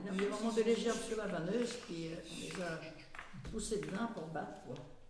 battage au rouleau ; Localisation Saint-Christophe-du-Ligneron
Catégorie Locution